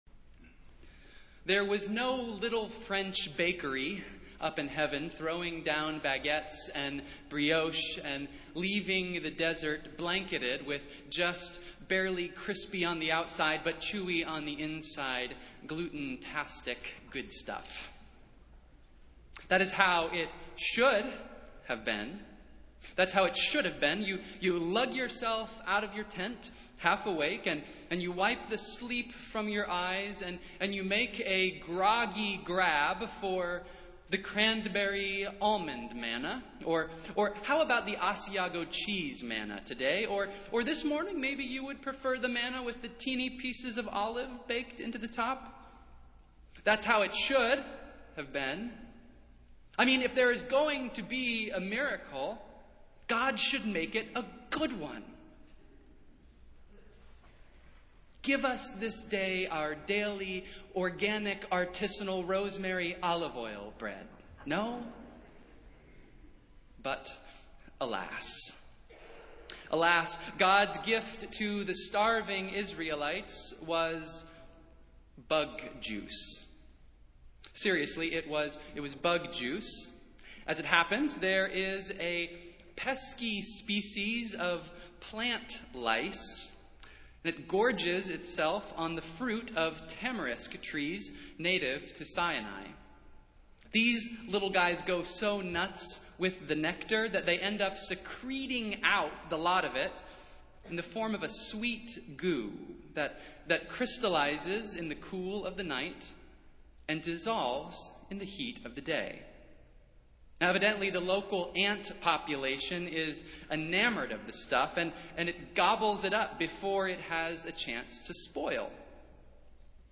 Festival Worship - Fourth Sunday in Lent